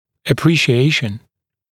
[əˌpriːʃɪ’eɪʃn][эˌпри:ши’эйшн]оценка